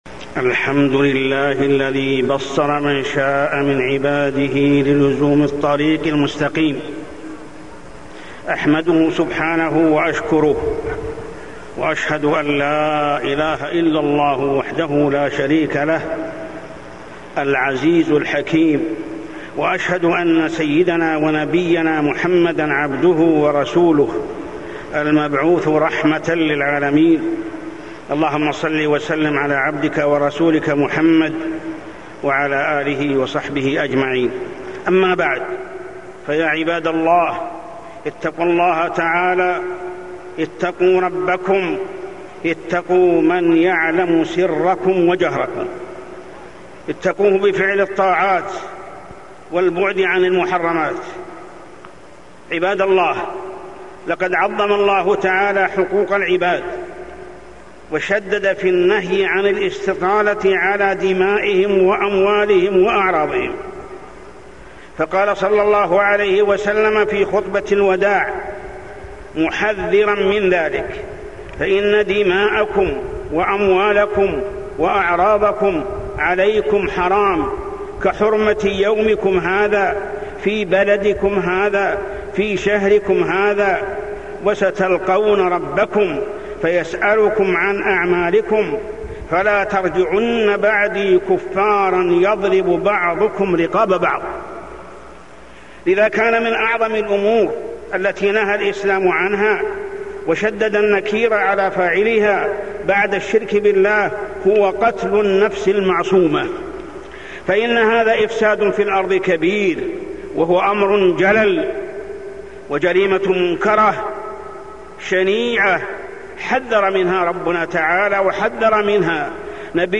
تاريخ النشر ٧ جمادى الأولى ١٤٢٥ هـ المكان: المسجد الحرام الشيخ: محمد بن عبد الله السبيل محمد بن عبد الله السبيل حرمة الدماء